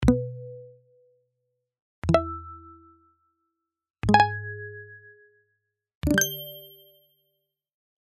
notifications.mp3